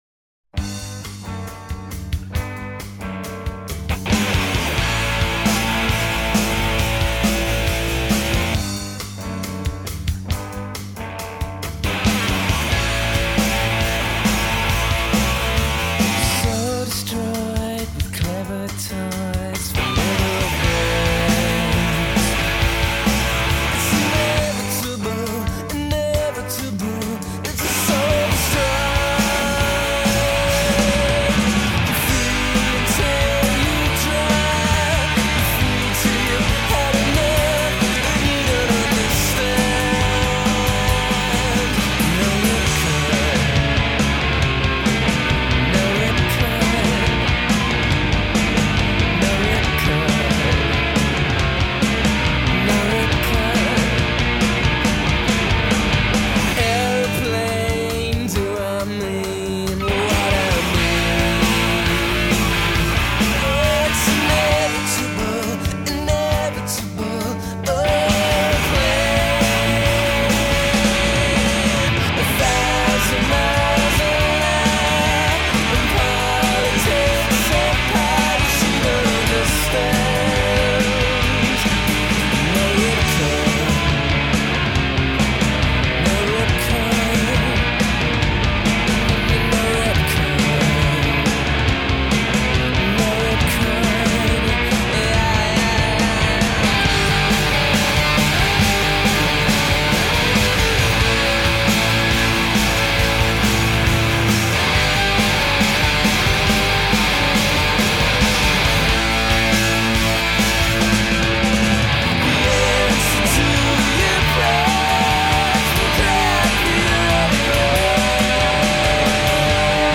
Альтернативный рок Alternative rock